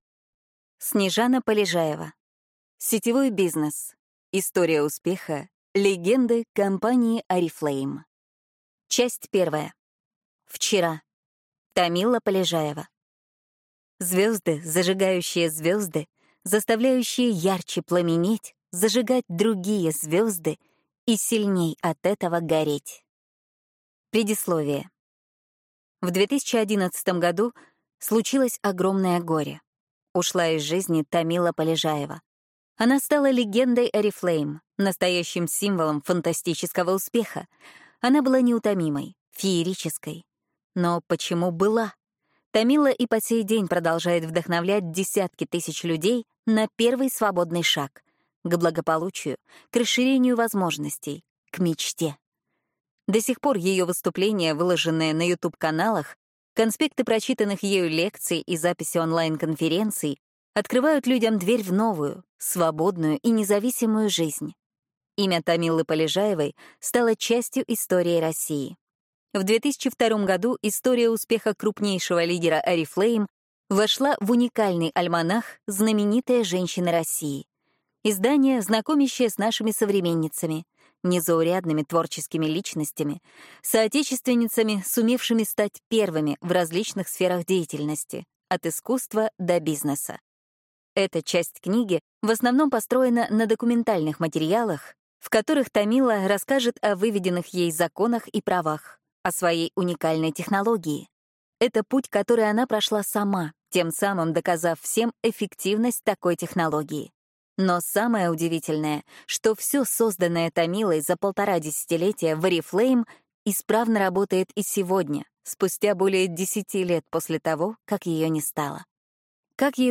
Аудиокнига Сетевой бизнес. Вчера. Сегодня. Завтра.